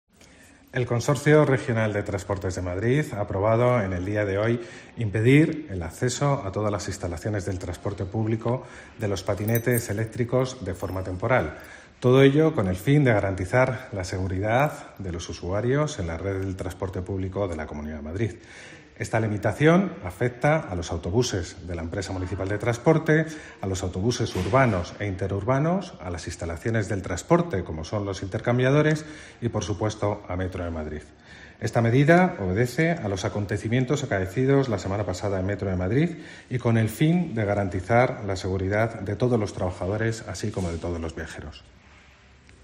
Escucha el comunicado de Jorge Rodrigo, consejero de vivienda, transporte e infraestructura